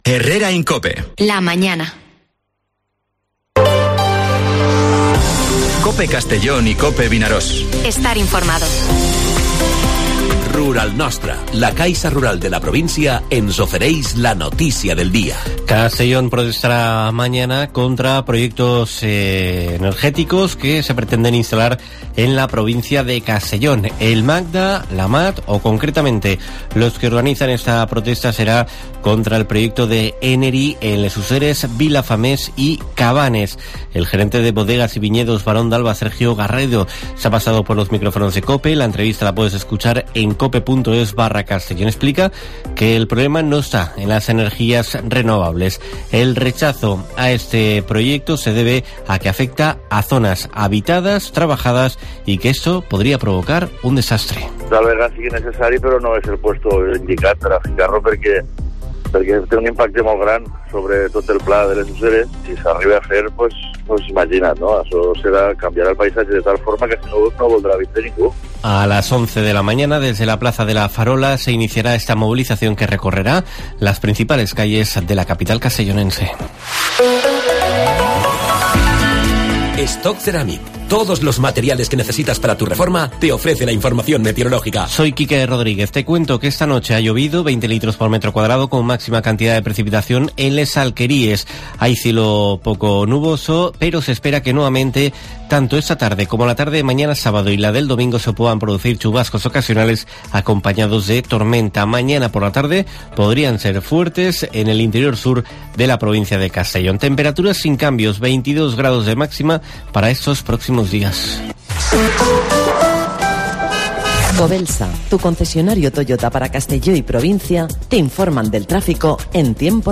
Informativo Herrera en COPE en la provincia de Castellón (19/05/2023)